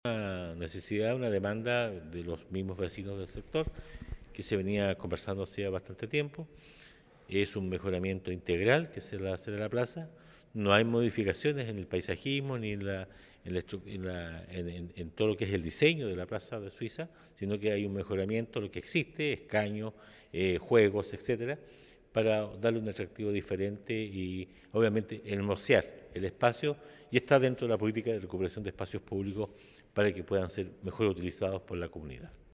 El proyecto de conservación de la Plaza Suiza tiene como objetivo principal la restauración y  mejora de áreas verdes y además de la implementación de nuevas infraestructuras, lo que ha sido solicitado por los vecinos y vecinas del sector, como lo señaló el alcalde Emeterio Carrillo.